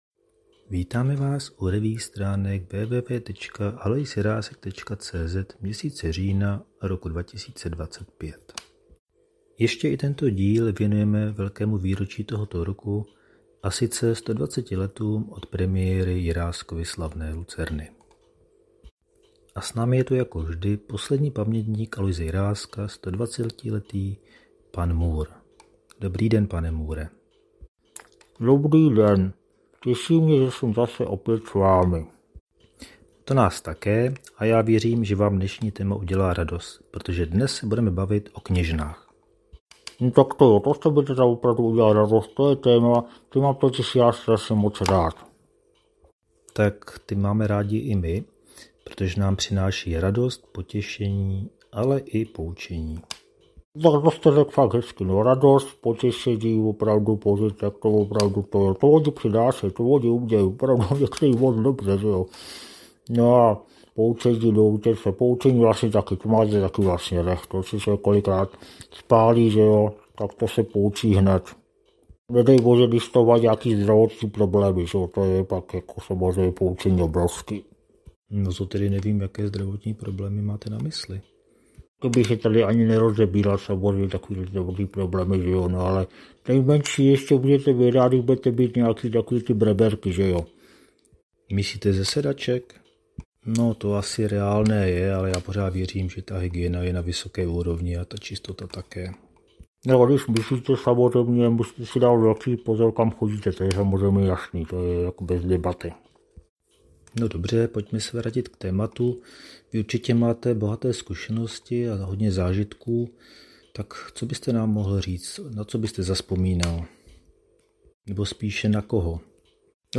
Další amatérský pokus o zvukovou revue se s obvyklou humornou nadsázkou ještě jednou vrací k letošnímu významnému výročí, 120 letům od slavné premiéry Jiráskovy Lucerny. V herecky vděčné a diváky milované roli kněžny se vystřídala celá plejáda skvělých českých hereček.